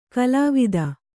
♪ kalāvida